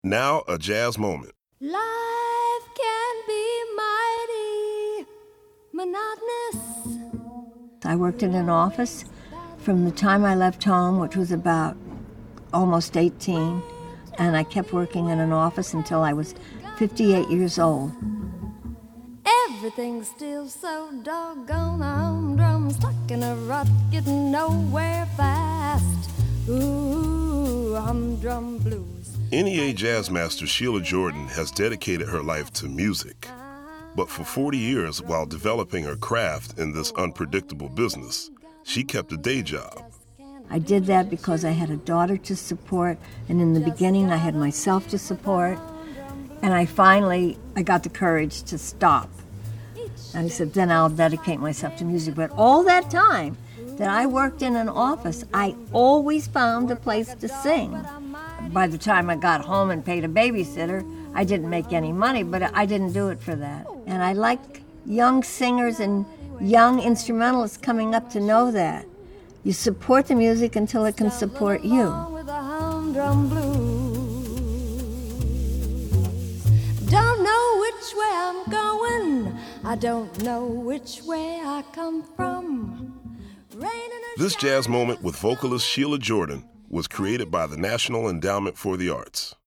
NOW, A JAZZ MOMENT…